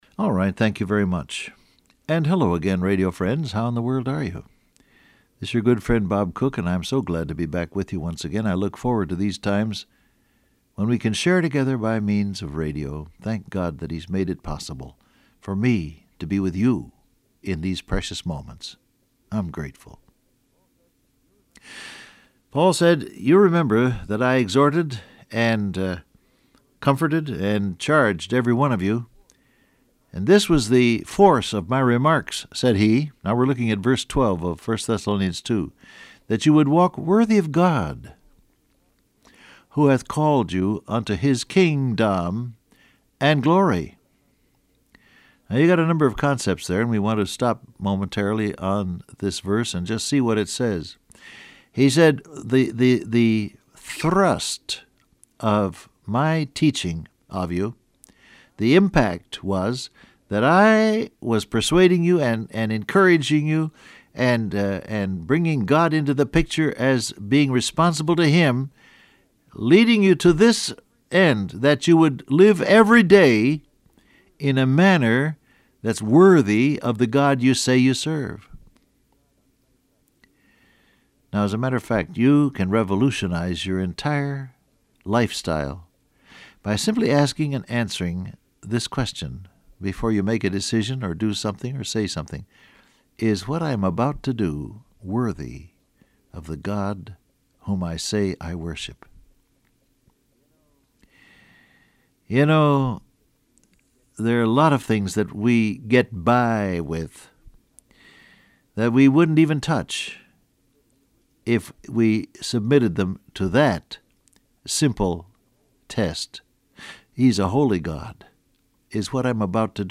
Download Audio Print Broadcast #7054 Scripture: 1 Thessalonians 2:12 Topics: Testimony , Real , King , Walk Transcript Facebook Twitter WhatsApp Alright, thank you very much, and hello again, radio friends.